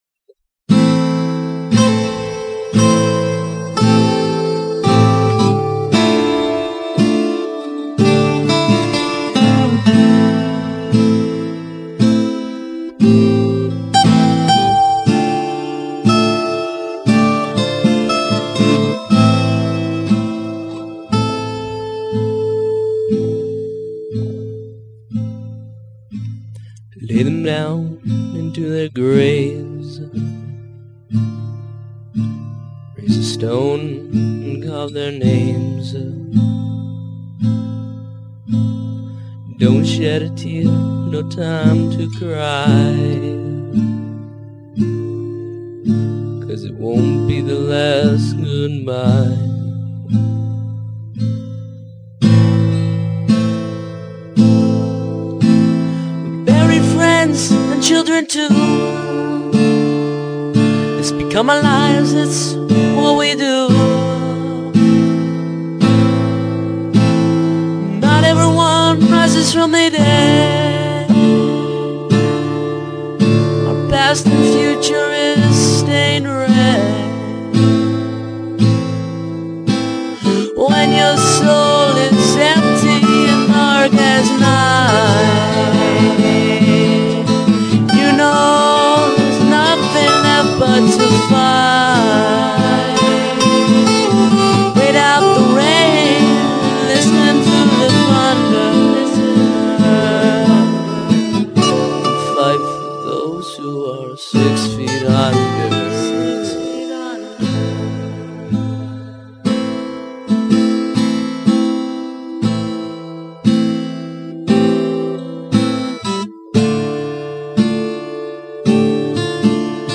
i love the soft blues